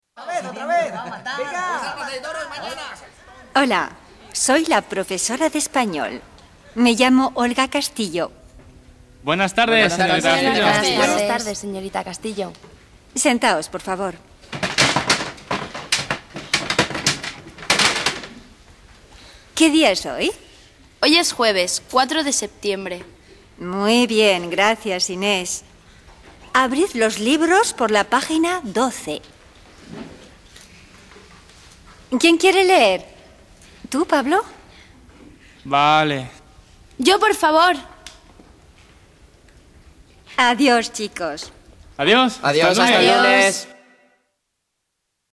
Se trata de un diálogo.
La escena pasa en una clase de español.